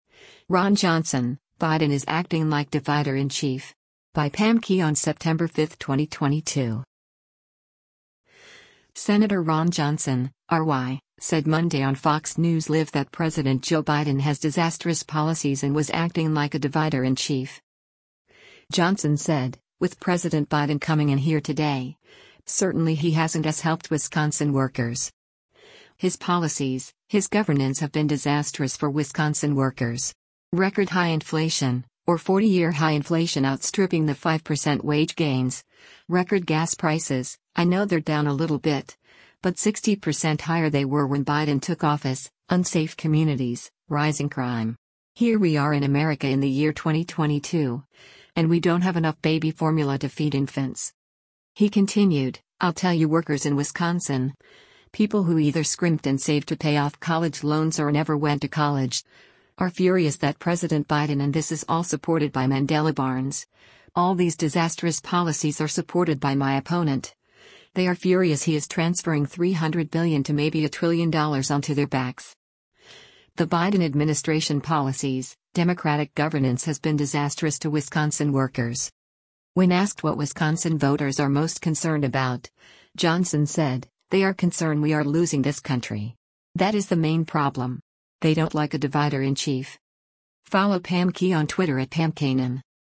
Senator Ron Johnson (R-WI) said Monday on “Fox News Live” that President Joe Biden has disastrous policies and was acting like a “divider-in-chief.”